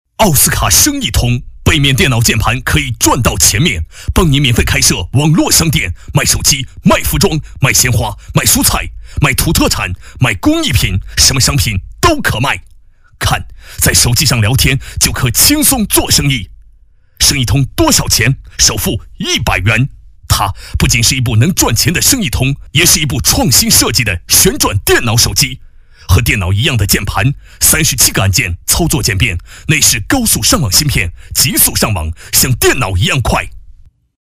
男声配音